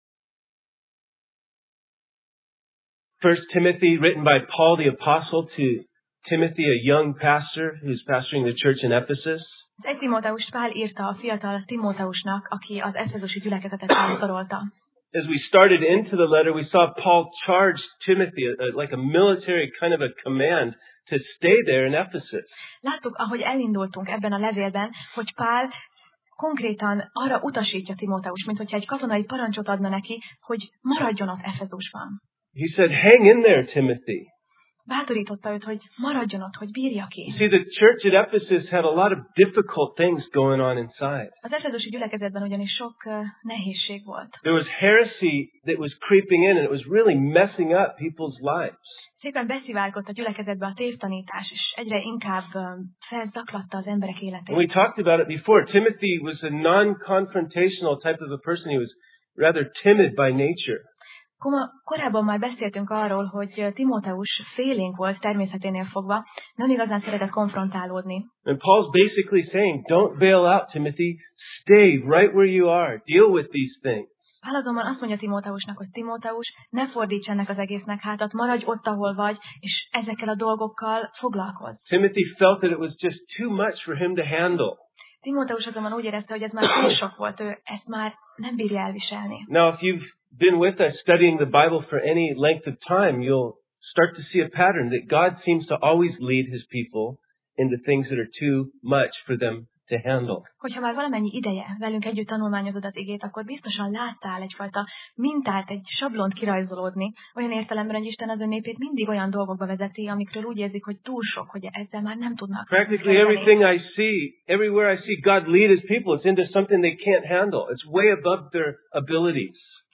Passage: 1Timóteus (1Timothy) 1:18-19 Alkalom: Vasárnap Reggel